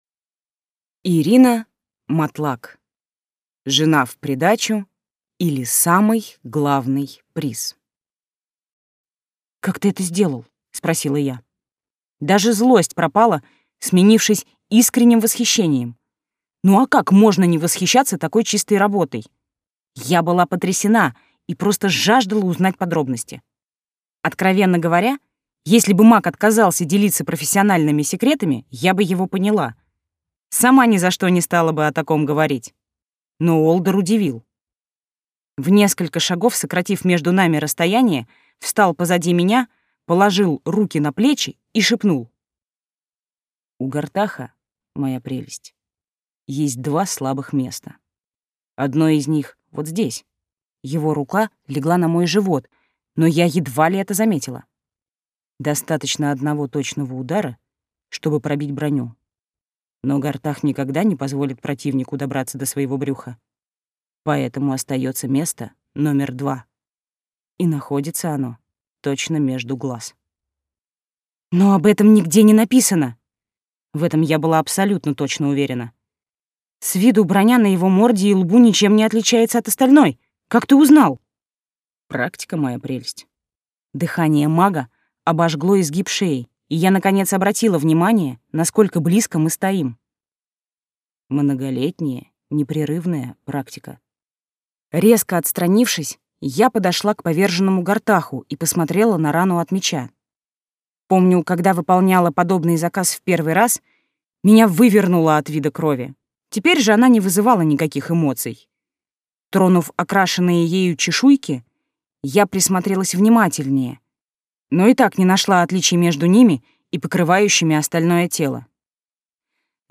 Аудиокнига Жена в придачу, или Самый главный приз | Библиотека аудиокниг